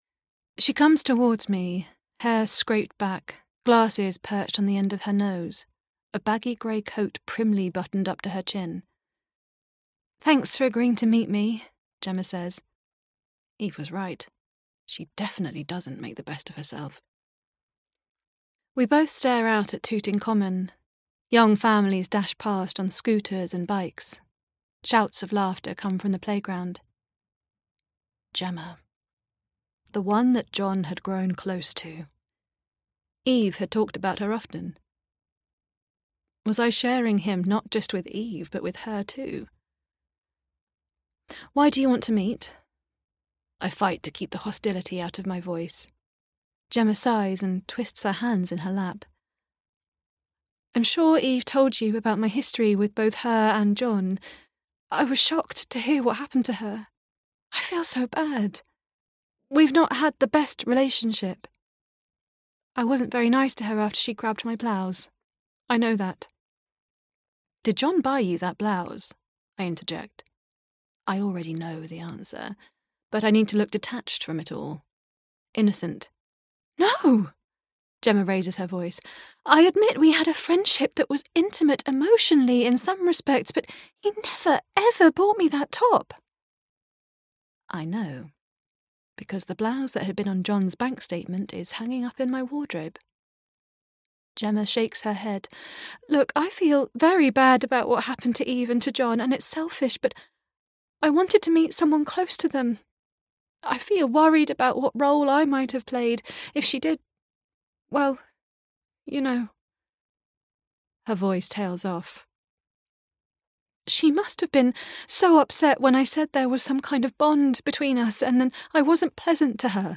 20/30's Neutral/RP,
Calm/Engaging/Reassuring
He’s Gone by Rebecca Collomosse (Neutral/RP) Hitler’s English Girlfriend – The Secret Diary of Unity Mitford (Heightened RP) The Will by Marivaux (Radio Drama)